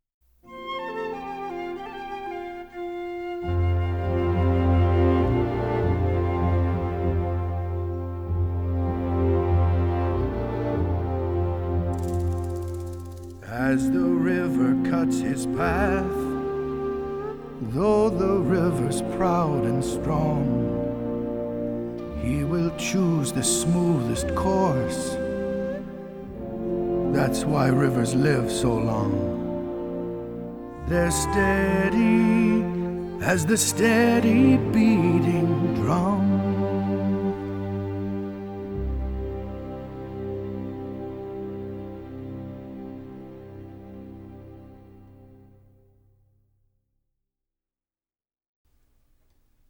На этой странице вы найдете саундтрек к мультфильму \